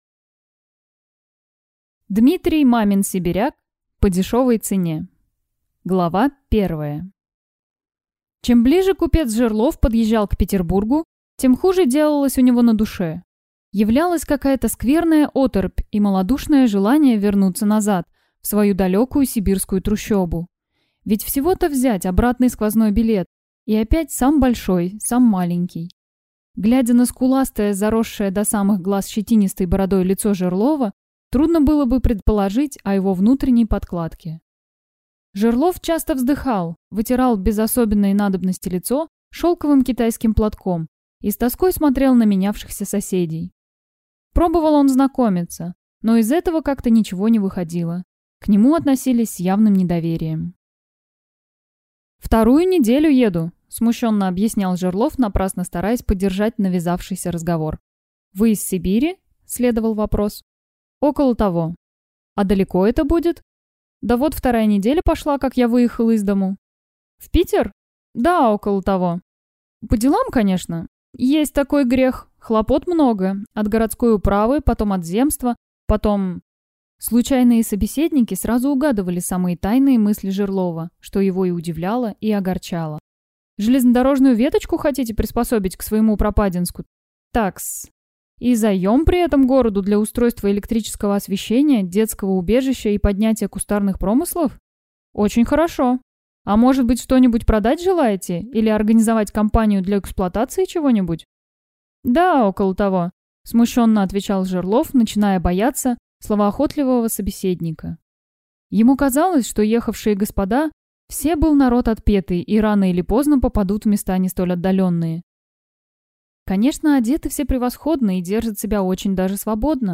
Аудиокнига По дешевой цене | Библиотека аудиокниг